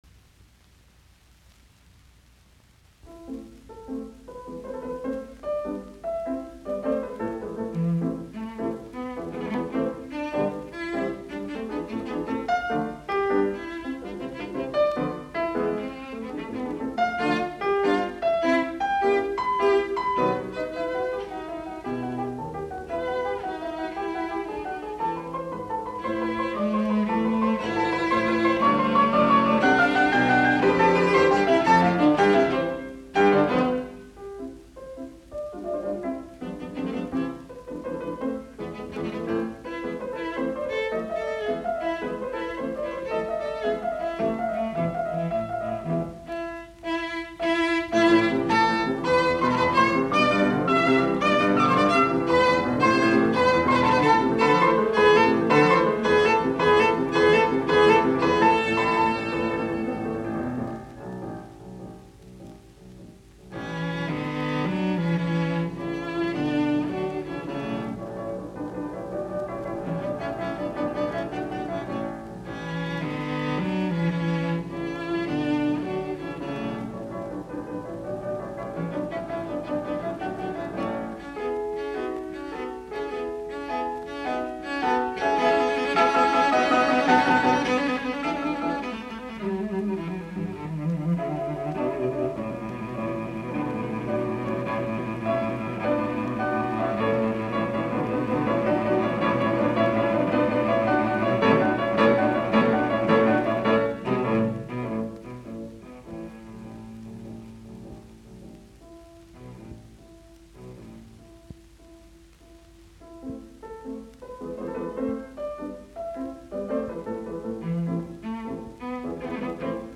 Sonaatit, sello, piano, op69, A-duuri
Soitinnus: Sello, piano.